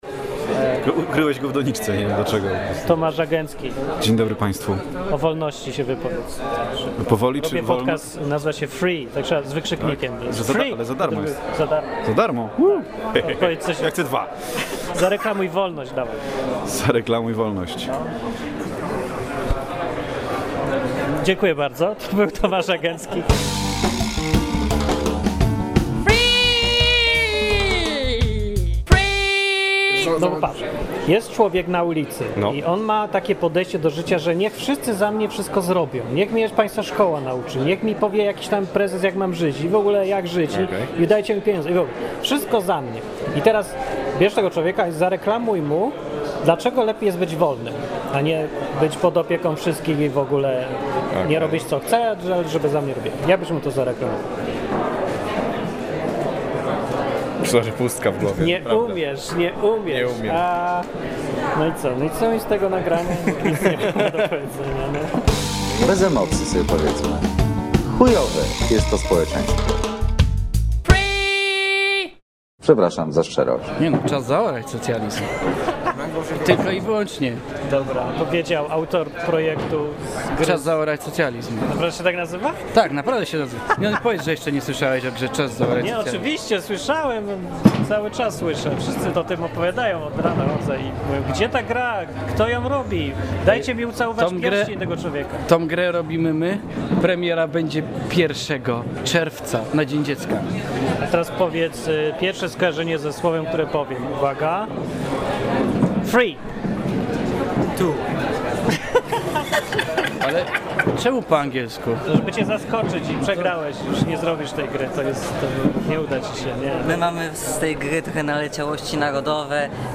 Darwinistów dorwałem podczas świętowania 5-lecia polskiego crowdfundingu w Poznaniu.